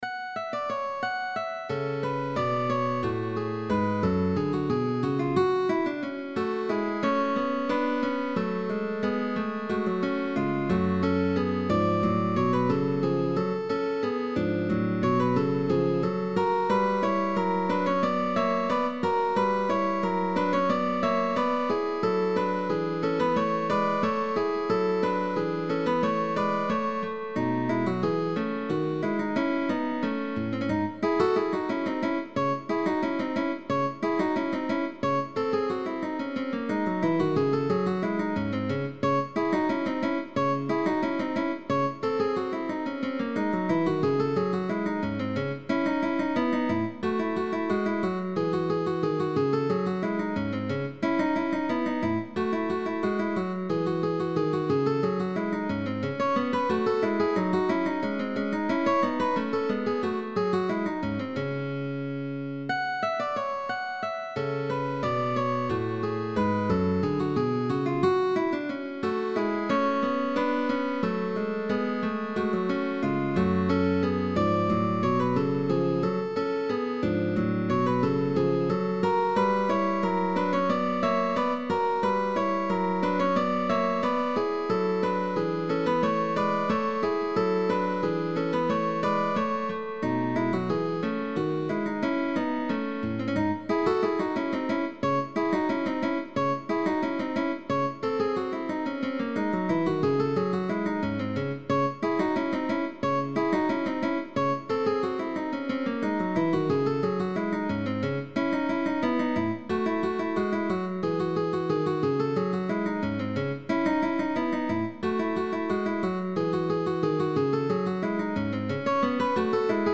GUITAR DUO
Tag: Baroque